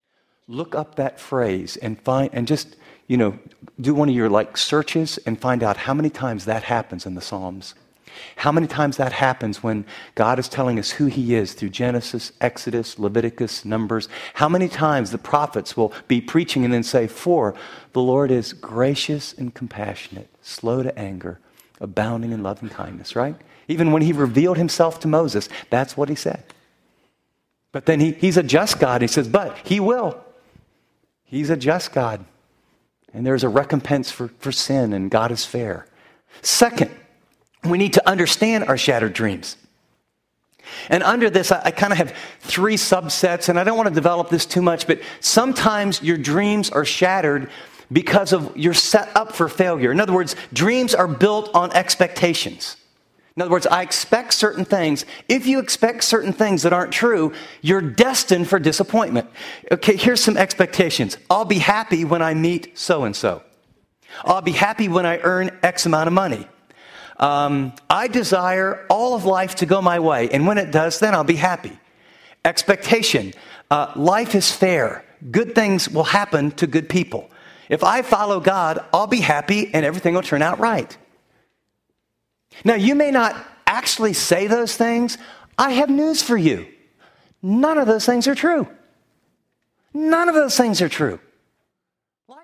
** Teaching Series **